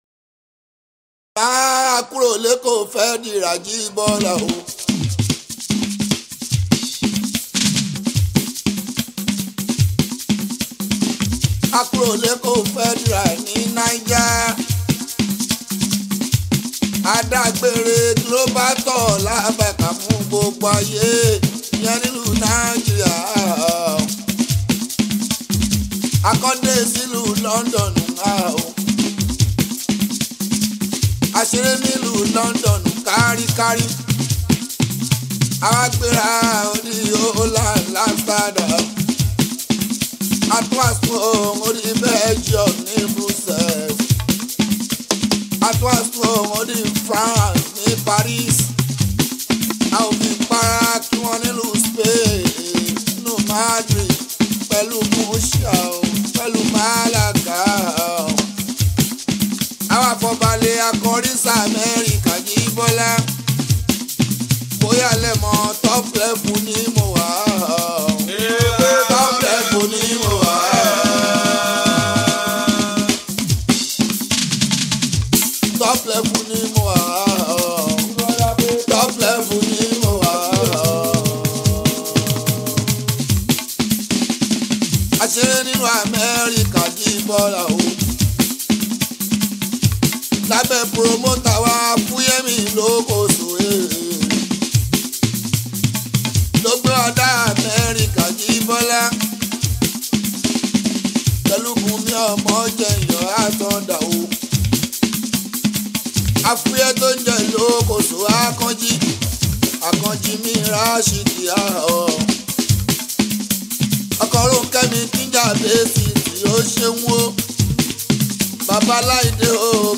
Nigerian Yoruba Fuji track
Yoruba Fuji Sounds